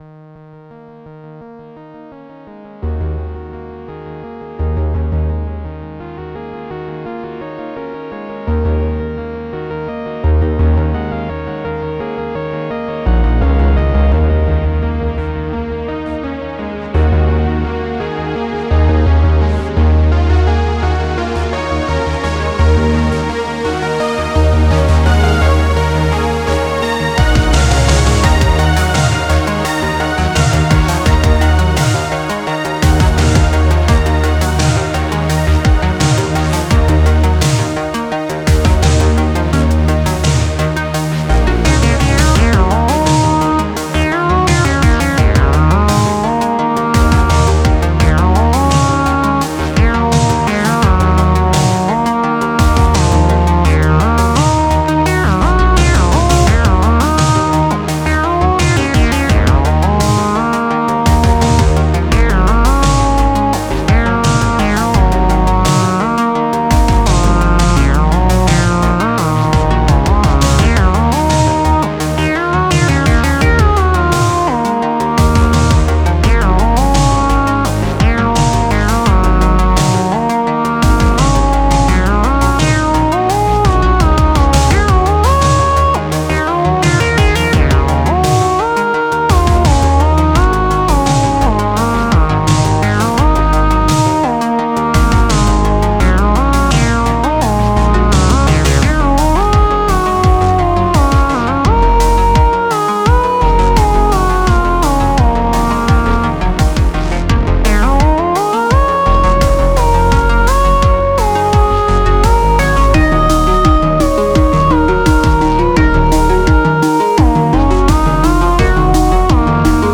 For this I experimented with 5 bar phrasing.